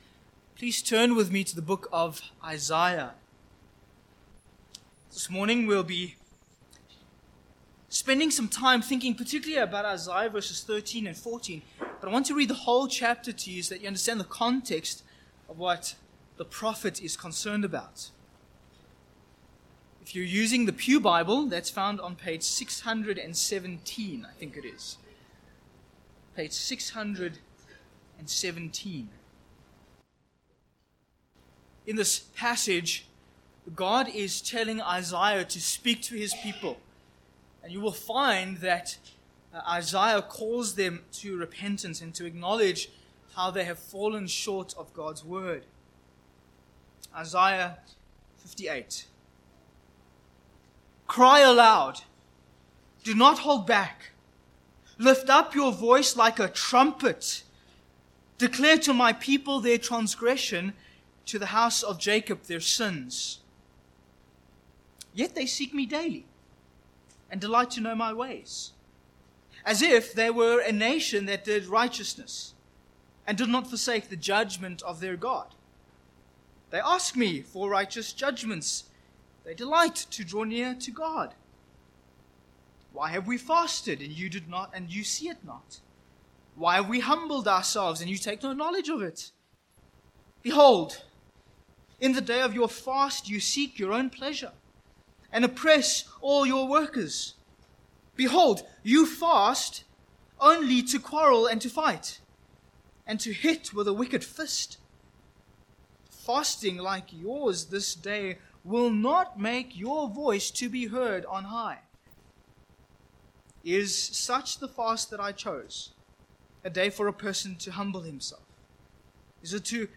Isaiah 58:13-14 Service Type: Morning « The Lord’s Day In Covenant The Lord’s day in Christ